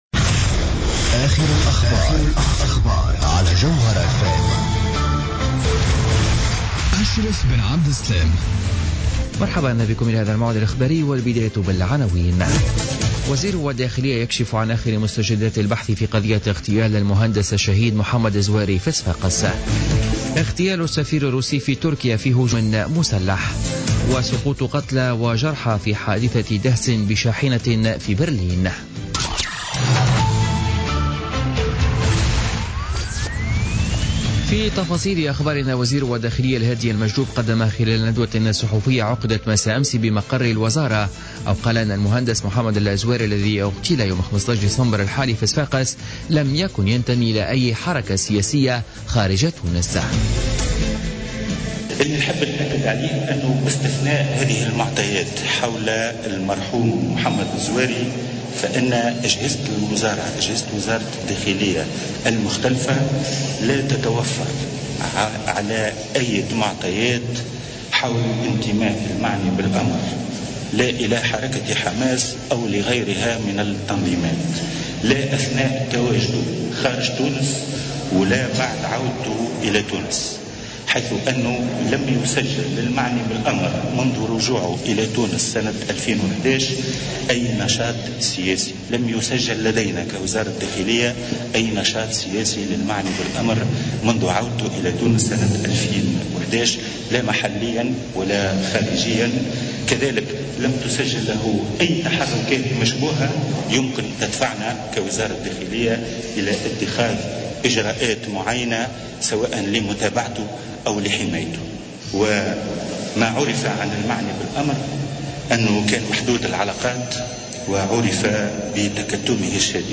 نشرة أخبار منتصف الليل ليوم الثلاثاء 20 ديسمبر 2016